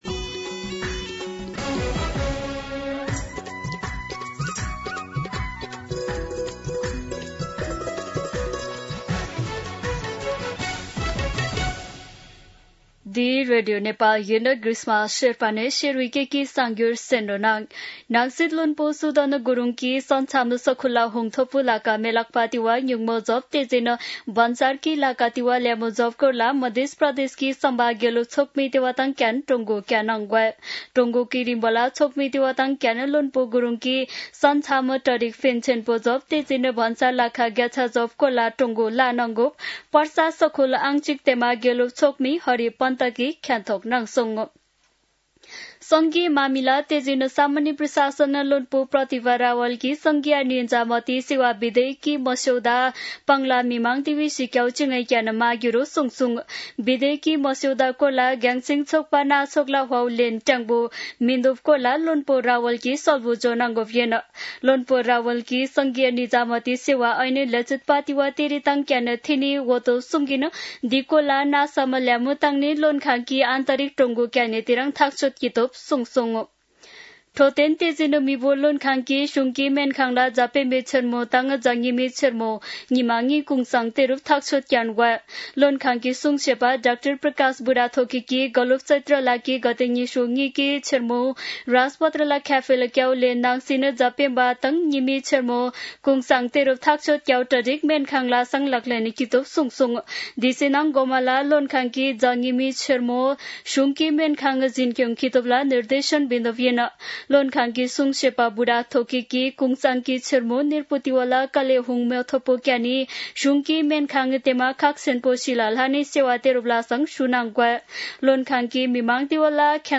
शेर्पा भाषाको समाचार : ५ वैशाख , २०८३
Sherpa-News-1-5.mp3